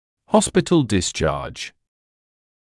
[‘hɔspɪtl dɪs’ʧɑːʤ][‘хоспитл дис’чаːдж]выписка из больницы (клиники, госпиталя)